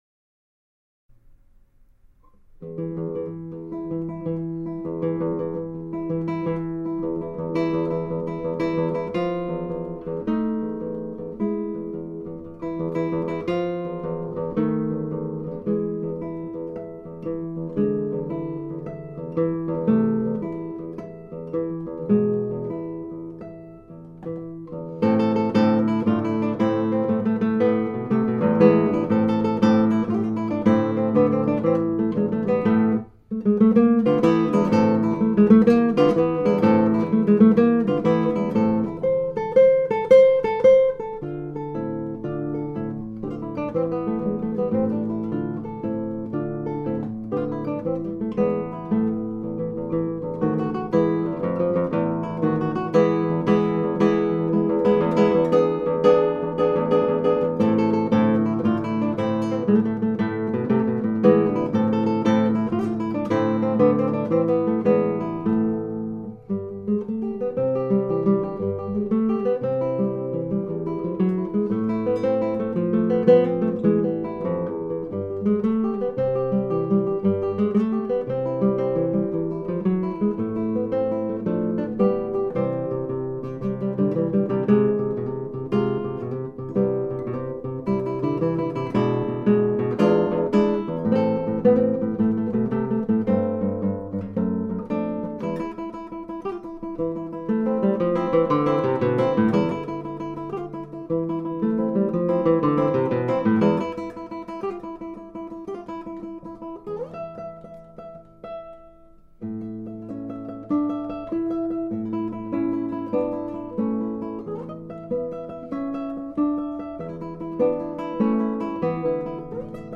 In former times I mostly played classical guitar (Tárrega, Albéniz, Sor, Bach etc.) nowadays I play more jazz (direction of hero Joe Pass) and brasilian (bossa nova, samba, choro etc.) stuff.
Some time ago when I was 18 I did a record mostly with classical stuff, which I do not play that often these days.